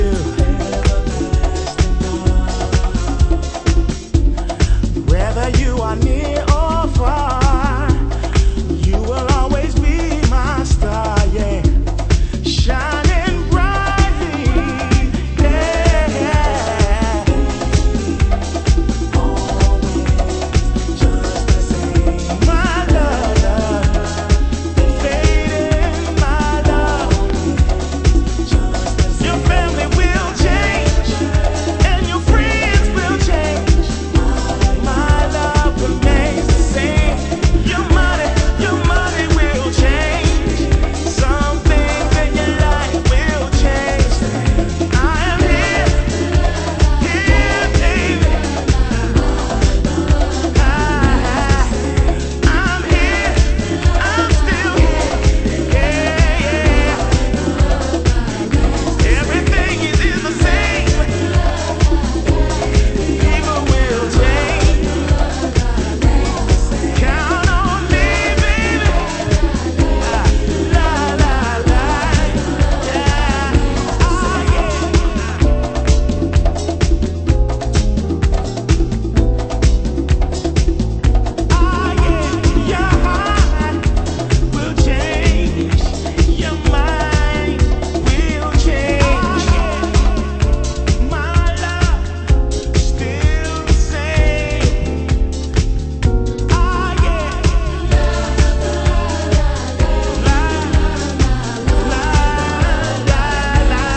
盤質：少しチリパチノイズ有